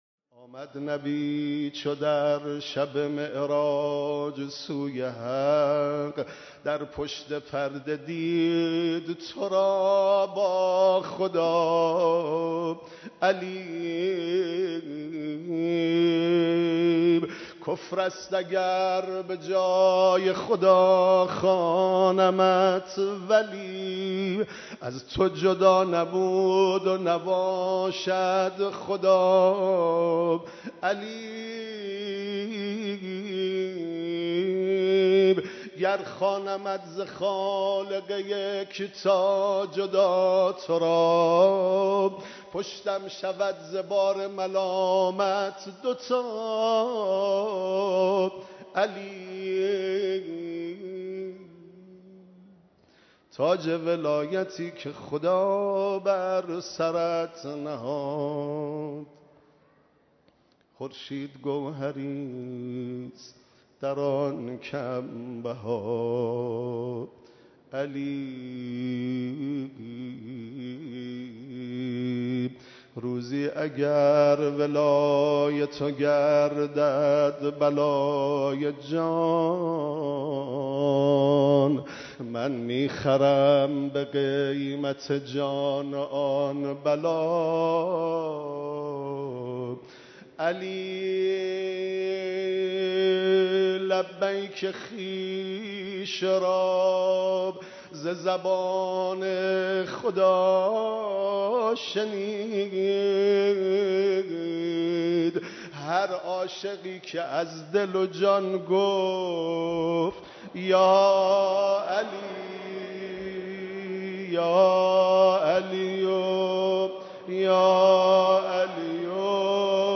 مراسم سوگواری سالروز شهادت مولای متقیان امام علی(ع)
مراسم سوگواری سالروز شهادت امام علی(ع) در حضور رهبر معظم انقلاب
مداحی